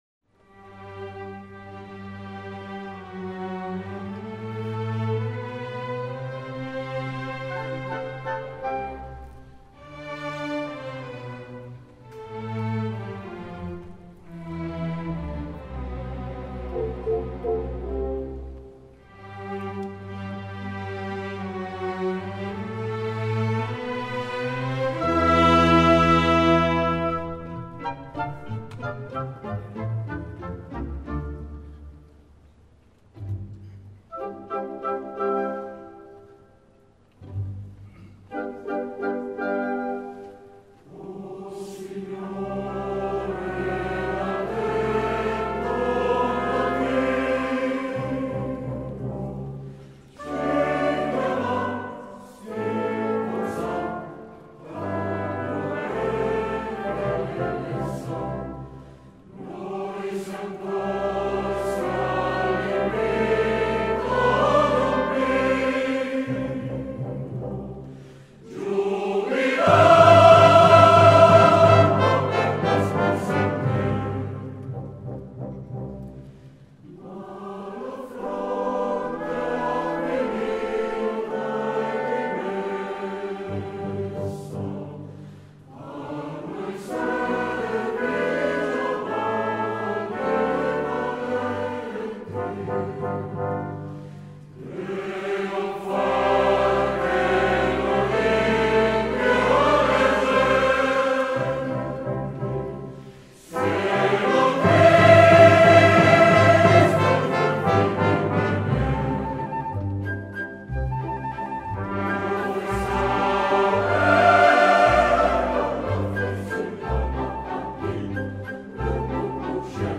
Il CD è stato registrato dall'Orchestra Sinfonica Giovanile del Piemonte dal vivo al concerto per la Festa della Repubblica, presso il teatro Alfieri di Torino (2 giugno 2005).
Coro Femminile Ensemble Vocale Arcadia